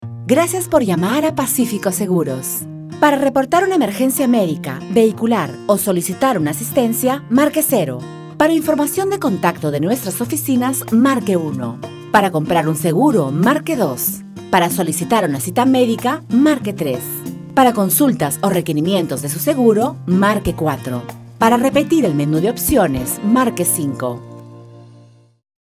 Espanhol - Peru
Voz Padrão - Grave 00:25